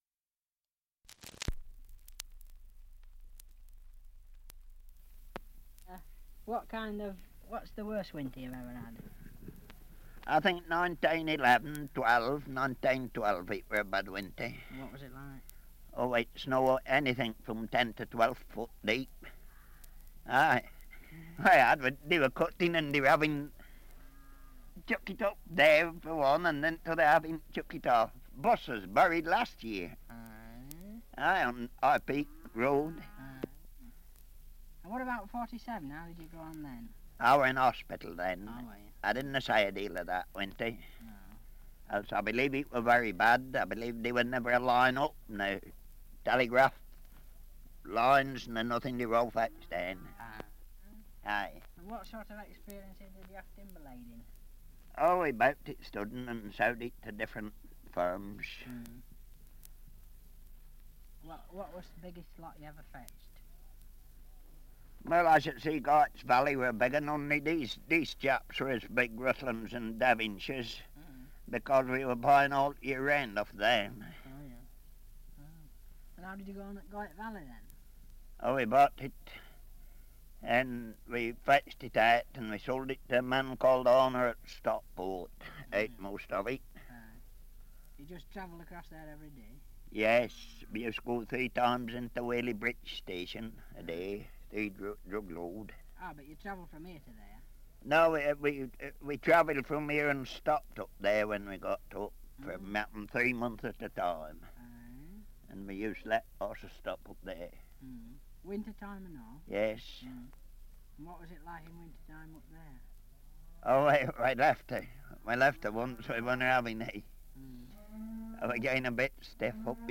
Survey of English Dialects recording in Youlgreave, Derbyshire
78 r.p.m., cellulose nitrate on aluminium